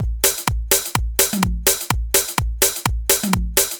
• drum roll techno hats and tom.wav
drum_roll_techno_hats_and_tom_6dP.wav